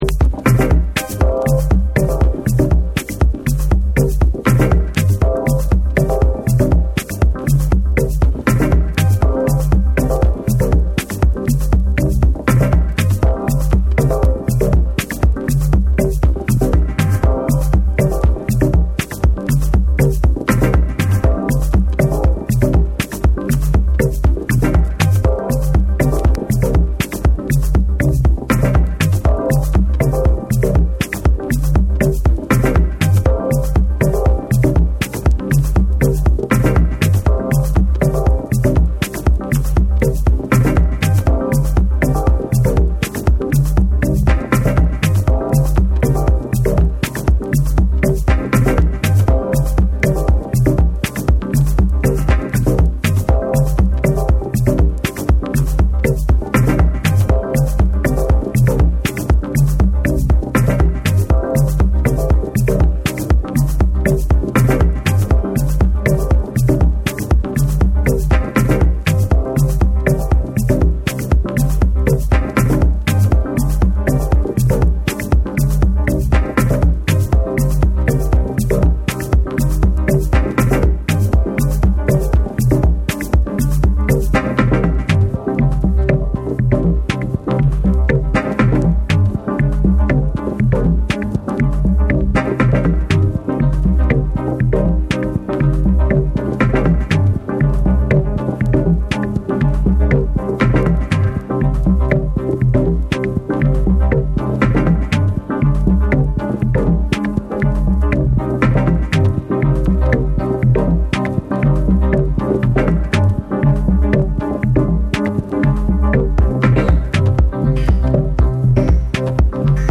ダビーなベースラインがリードを取りながら、音の粒のような緻密で繊細なサウンドが絡み合うスモーキーなダブ・テクノを収録。